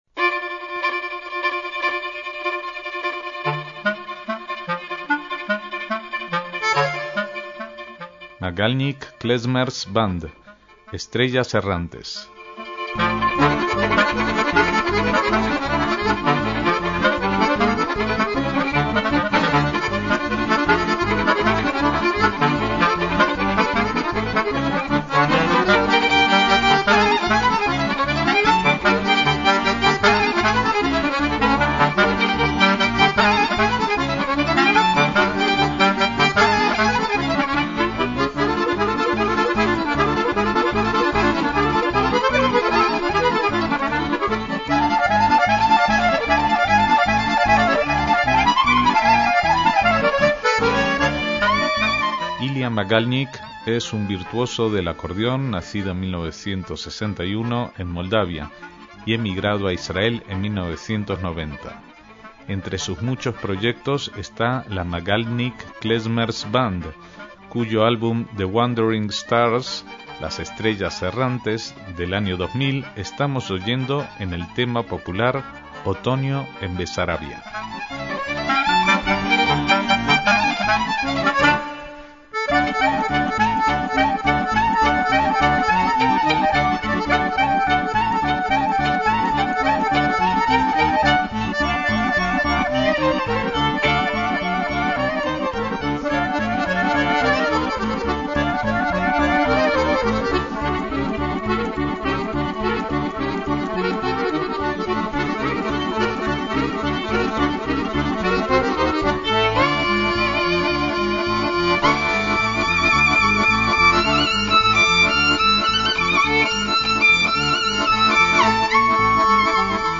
acordeón
violín
clarinete